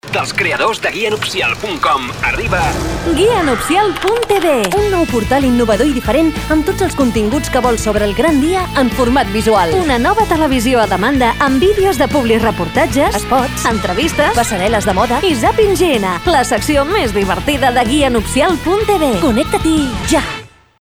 A continuació us presentem les falques de publicitat que s’han emès a ràdio Flaix Bac: